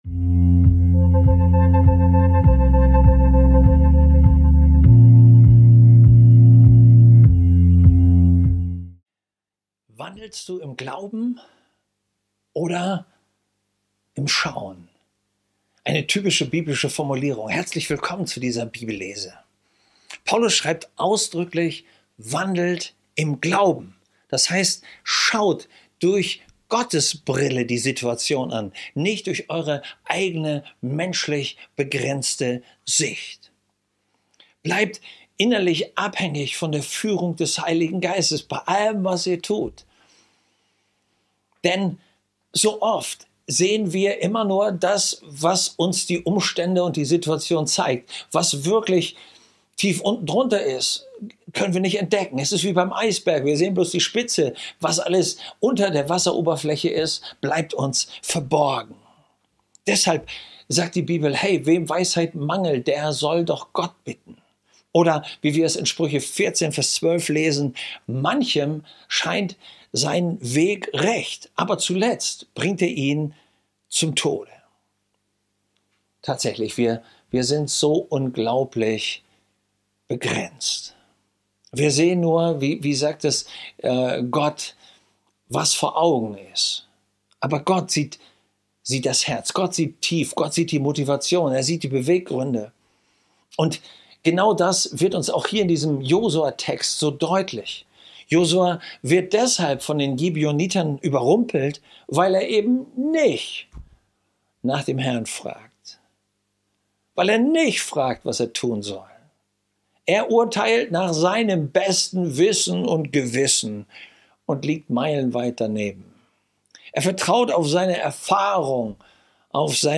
Bibellesen